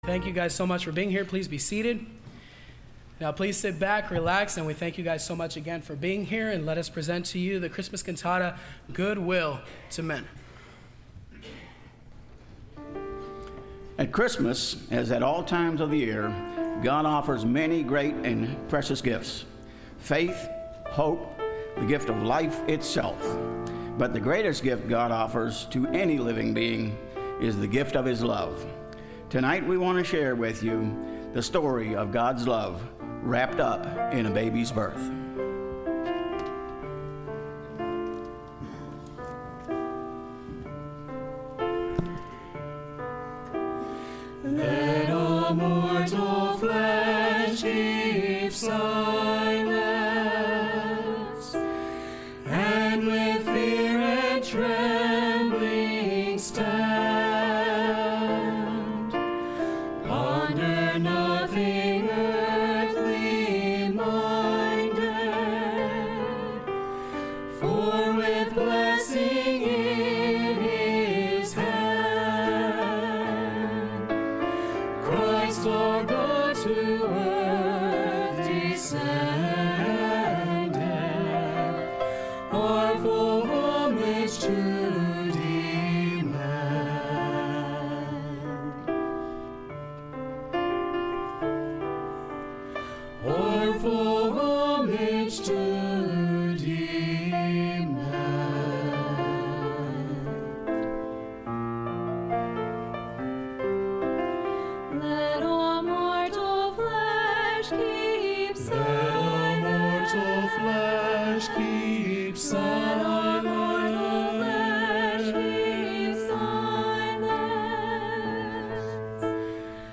Service Type: Sunday Evening Choir